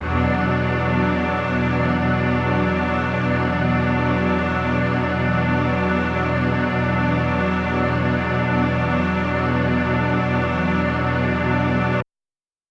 SO_KTron-Ensemble-E7:9.wav